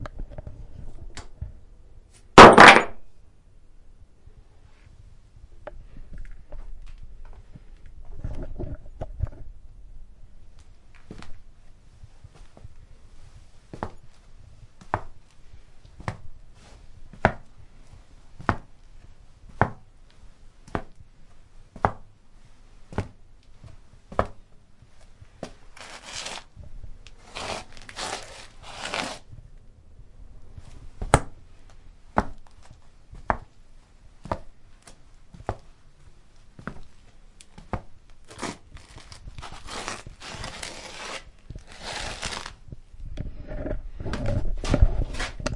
钥匙01
标签： 护甲 脚步声 叮当 邮件 房子 金属 金陵 汽车 钥匙 影响
声道立体声